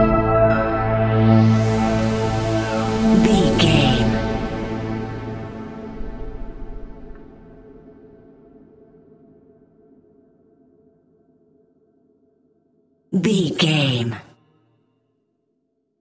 Aeolian/Minor
ominous
dark
suspense
eerie
piano
percussion
strings
synthesiser
atmospheres